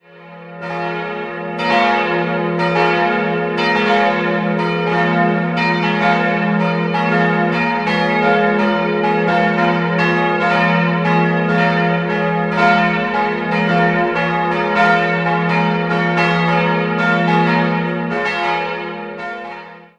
Einen richtigen Turm gibt es nicht, die Glocken hängen stattdessen in einem niedrigen Glockenhaus. 3-stimmiges Geläut: f'-as'-b' Alle Glocken wurden 1972 von Rudolf Perner in Passau gegossen.